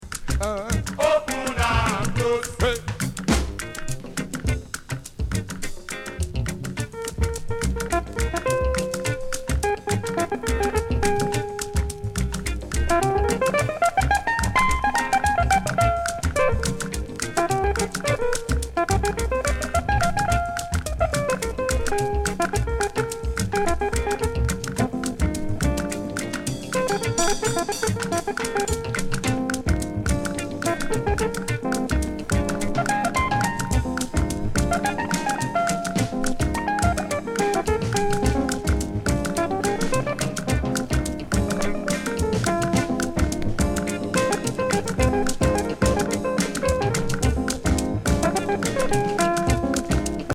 離れわざ的なゆるゆるブレイクも流石なアフロ・グルーヴ名曲